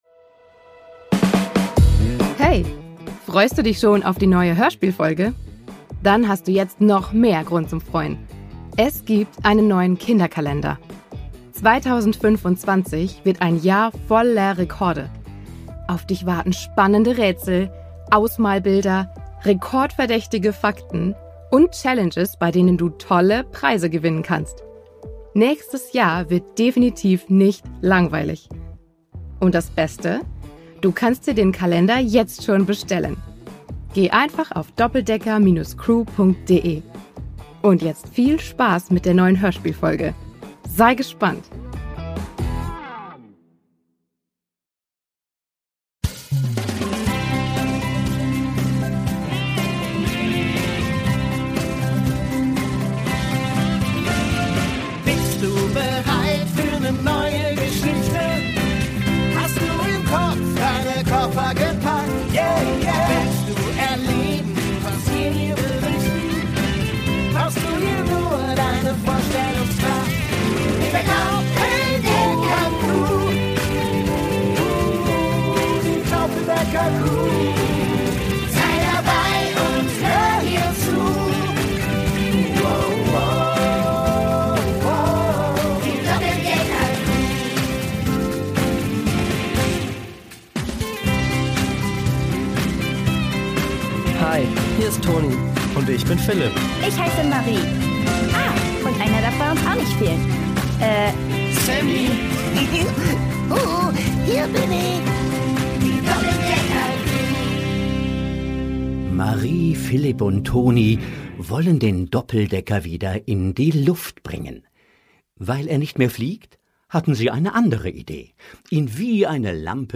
Brasilien 5: Gefahr am Fluss | Die Doppeldecker Crew | Hörspiel für Kinder (Hörbuch) ~ Die Doppeldecker Crew | Hörspiel für Kinder (Hörbuch) Podcast